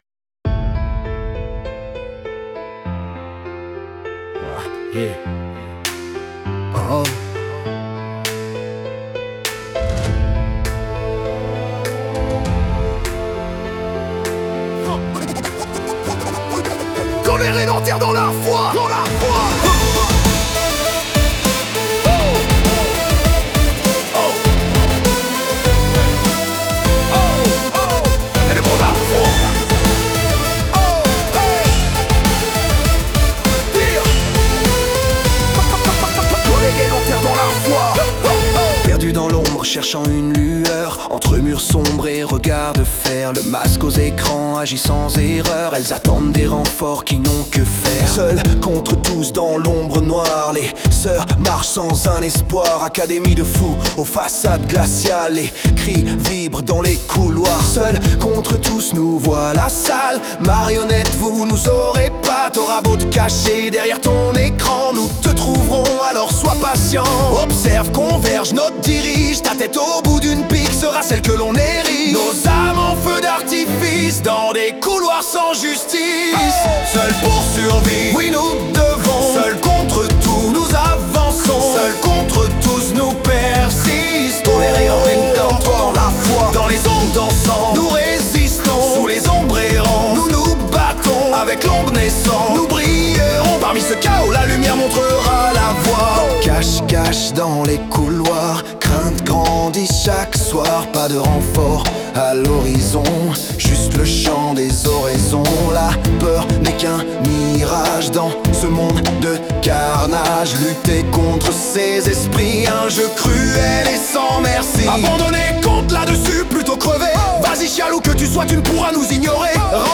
Réalisé sur PC avec Logic Audio.
• Format : ogg (stéréo)